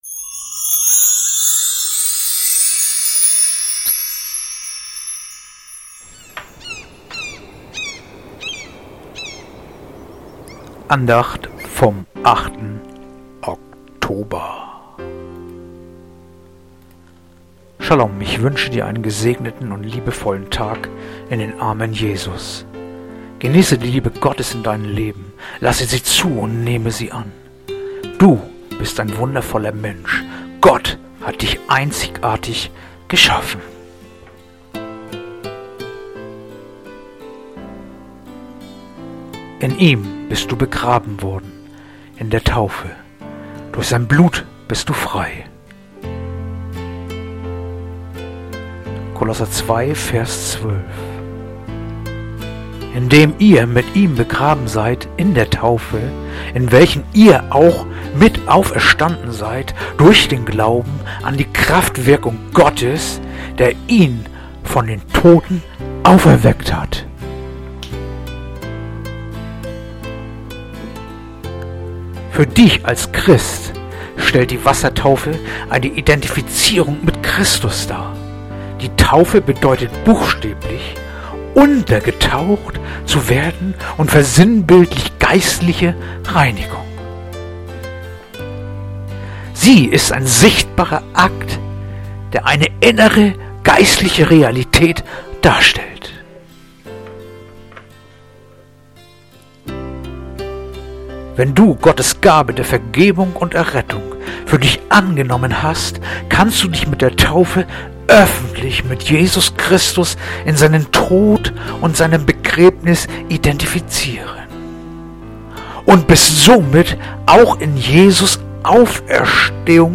Andacht-vom-08-Oktober-Kolosser-2-12.mp3